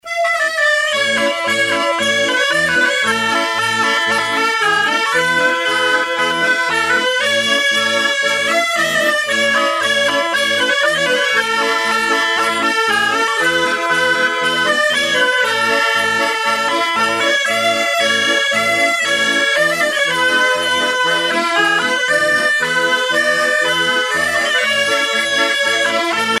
gestuel : à marcher
Pièce musicale éditée